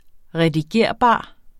Udtale [ ʁεdiˈgeɐ̯ˀˌbɑˀ ]